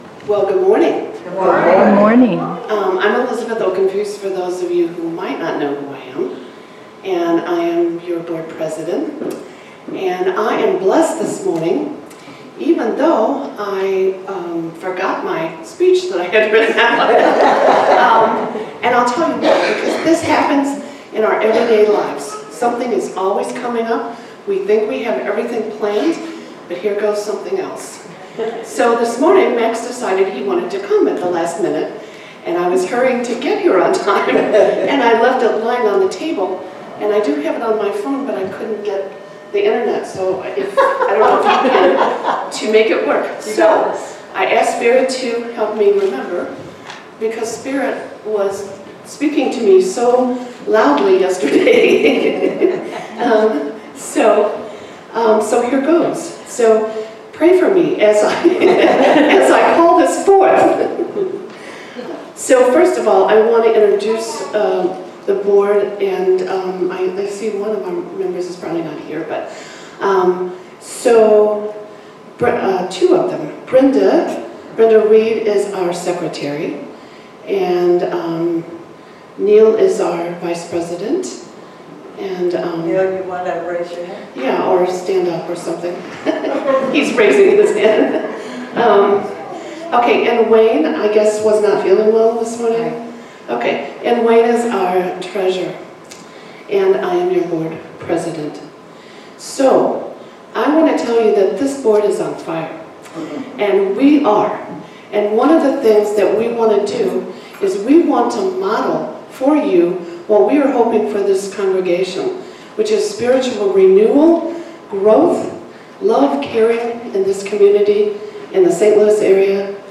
Spiritual Leader Series: Sermons 2024 Date